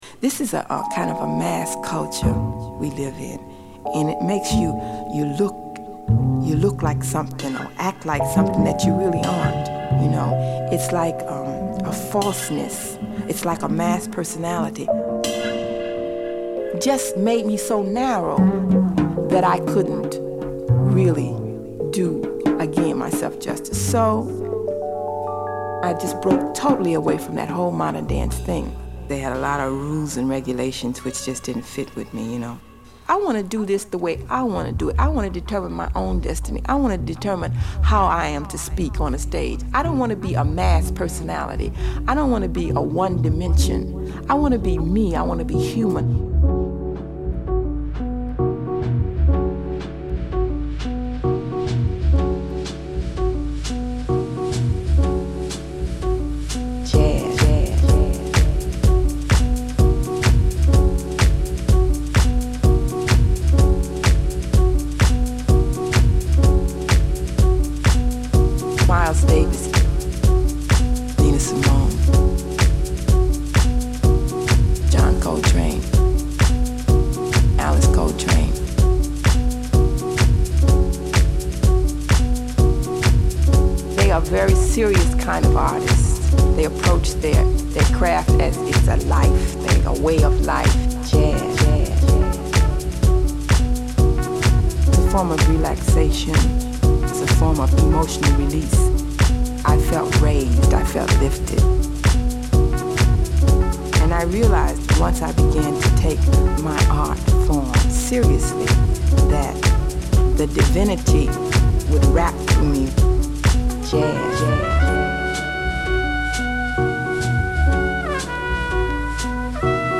Jazzy House , Mellow Groove